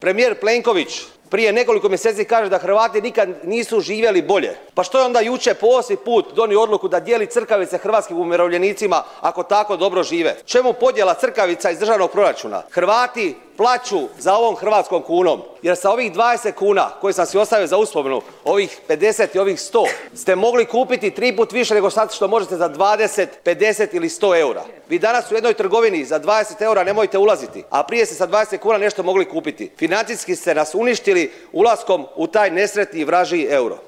Uoči početka rasprave saborski zastupnik Hrvatskih suverenista Marijan Pavliček zatražio je stanku poručivši kako je žalosno što u Saboru nema guvernera HNB-a. Gdje je nestao čovjek, zapitao je Pavliček ističući kako je uoči ulaska u eurozonu guverner bio stalno prisutan u medijima i uvjeravao građane da neće doći do poskupljenja i da će imati bolji standard, no dogodilo se upravo suprotno.